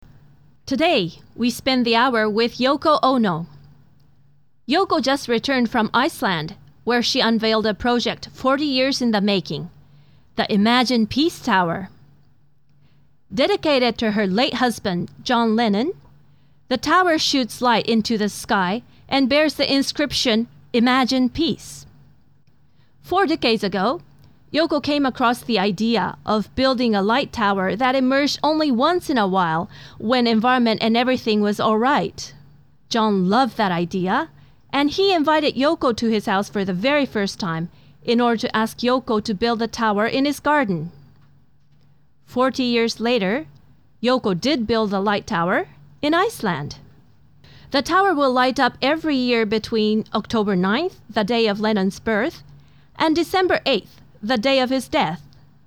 バイリンガルナレーターボイスサンプル